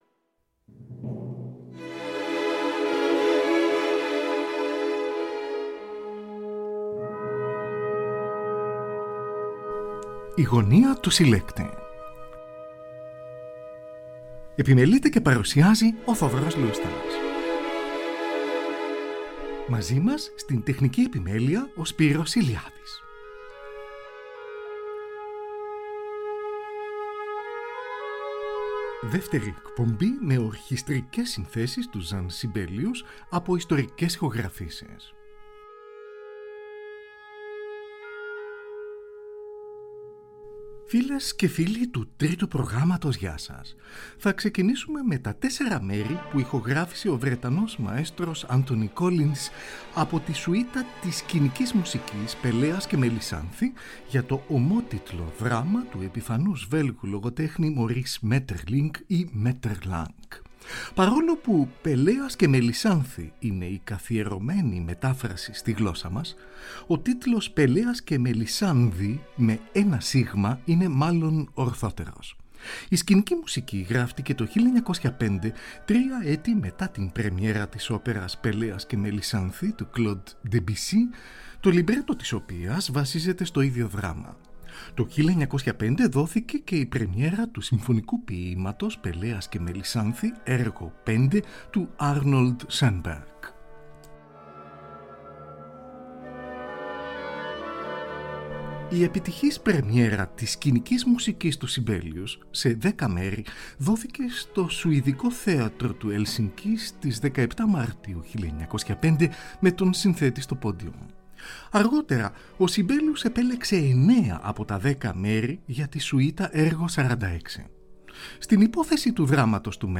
Ορχηστρικές Συνθέσεις
Πρώτη παγκόσμια ηχογράφηση.